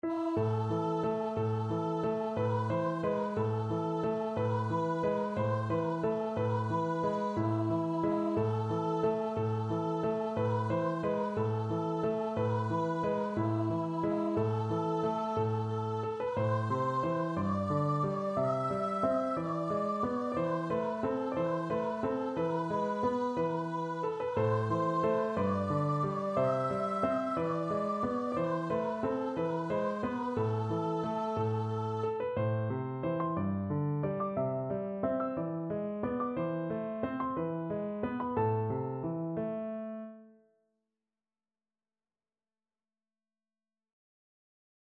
Free Sheet music for Voice
Voice
6/8 (View more 6/8 Music)
A minor (Sounding Pitch) (View more A minor Music for Voice )
~ = 90 Munter